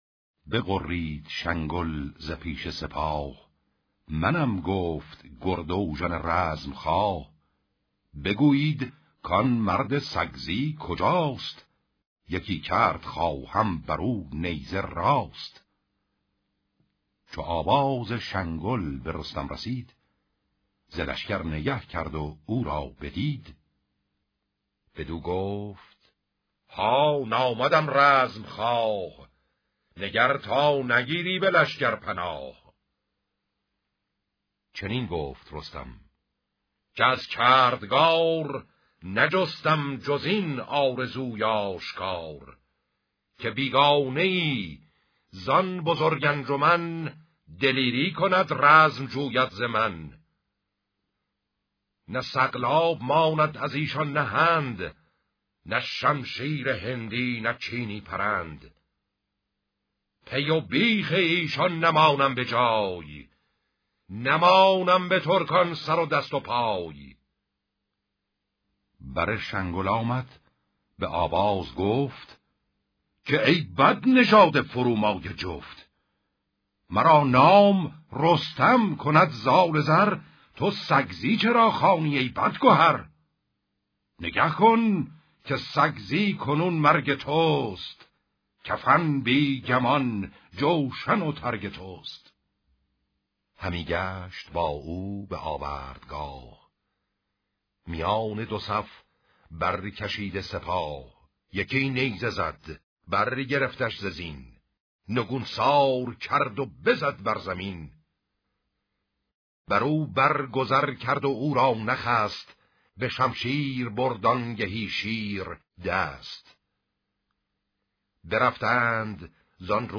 شاهنامه خوانی